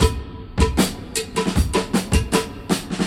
78 Bpm '00s Breakbeat Sample C Key.wav
Free drum loop sample - kick tuned to the C note.
.WAV .MP3 .OGG 0:00 / 0:03 Type Wav Duration 0:03 Size 531,5 KB Samplerate 44100 Hz Bitdepth 16 Channels Stereo Free drum loop sample - kick tuned to the C note.
78-bpm-00s-breakbeat-sample-c-key-ekE.ogg